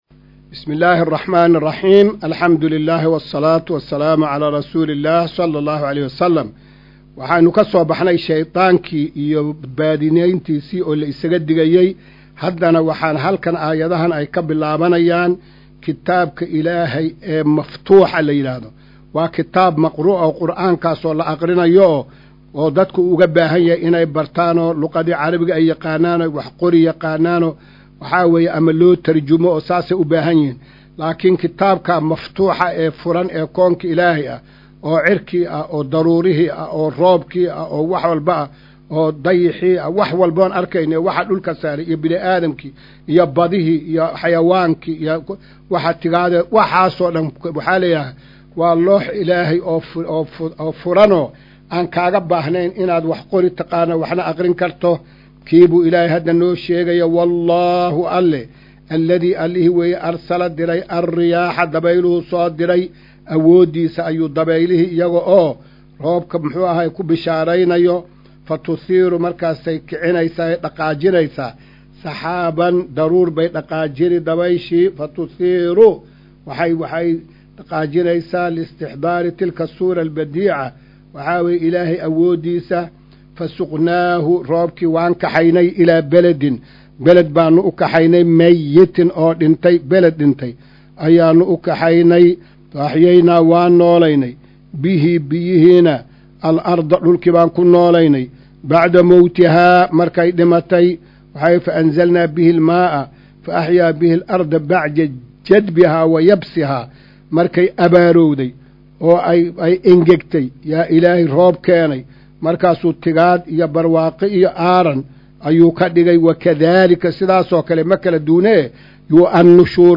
Maqal:- Casharka Tafsiirka Qur’aanka Idaacadda Himilo “Darsiga 206aad”